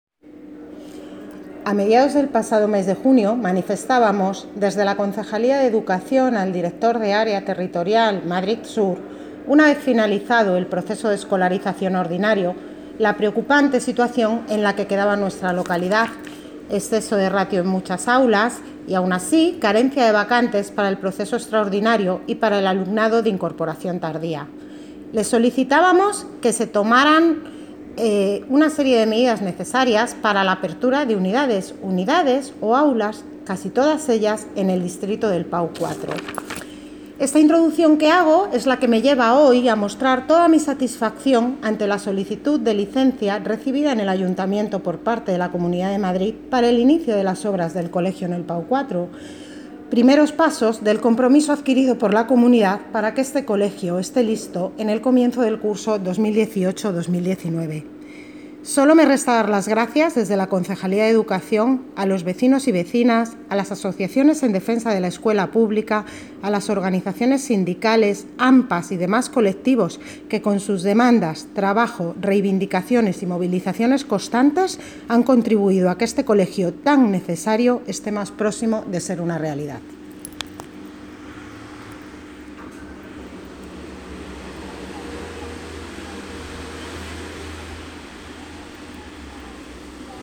Audio - Isabel Cruceta (Concejala de Educación ) Sobre Colegio PAU 4